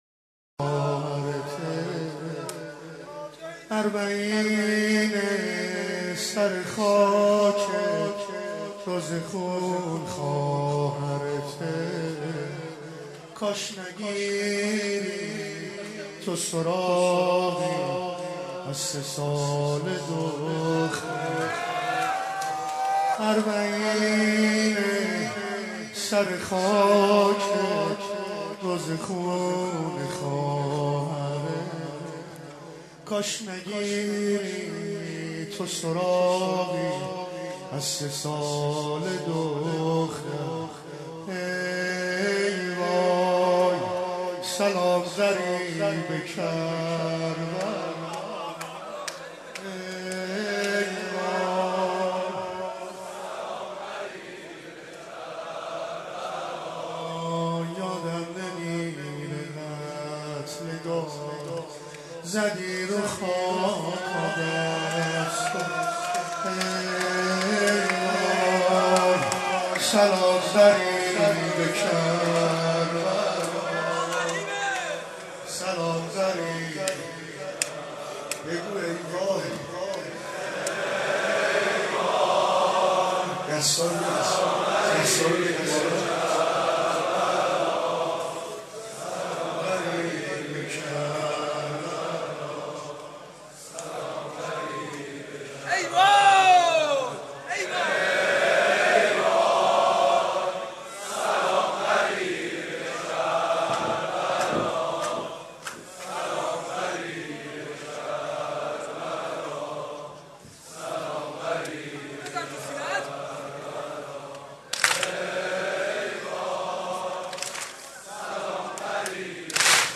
دانلود نوحه و مداحی جدید ویژه «اربعین حسینی» (مهر ۱۴۰۰)
به مناسبت فرارسیدن ایام اربعین حسینی ۱۴۰۰، گلچین نوحه و مداحی‌ها، با نوای مداحان معروف اهل بیت را برای شما آماده کرده‌ایم.